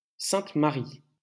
来自 Lingua Libre 项目的发音音频文件。 语言 InfoField 法语 拼写 InfoField Sainte-Marie 日期 2018年8月9日 来源 自己的作品